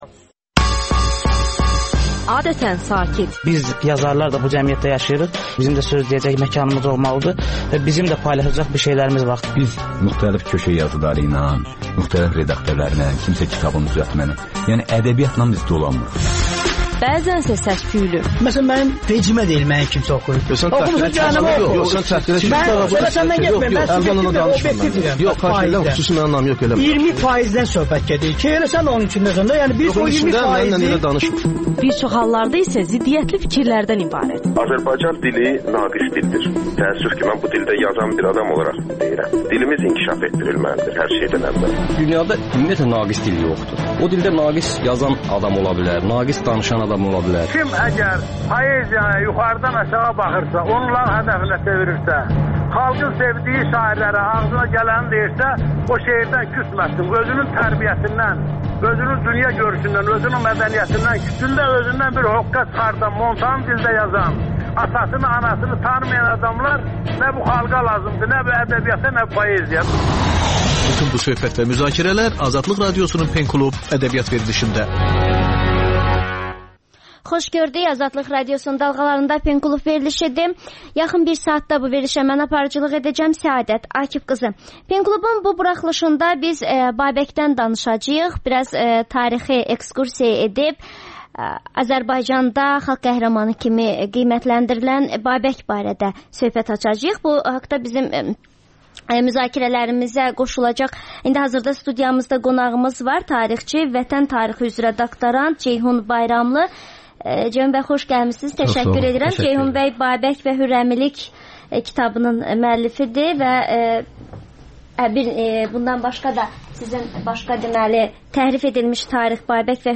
Azərbaycanda və dünyda baş verən hadisələrin ətraflı analizi, təhlillər, müsahibələr.